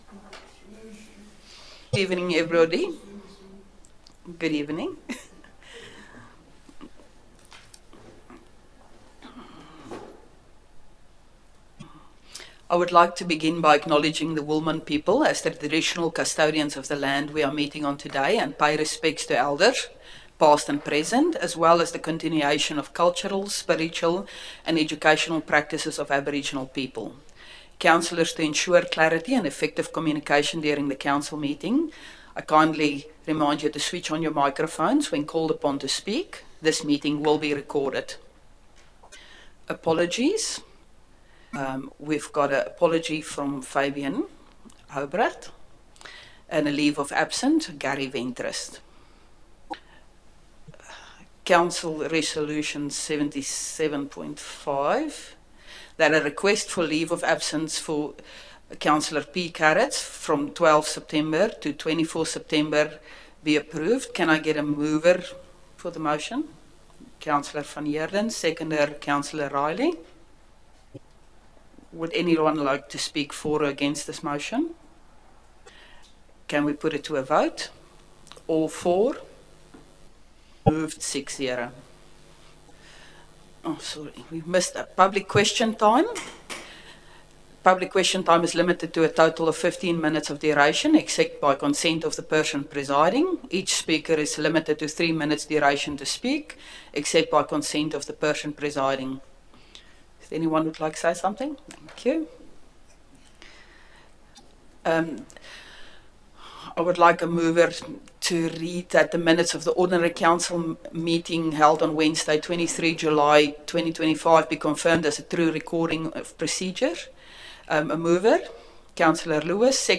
Ordinary Council Meeting August 2025 » Shire of Boddington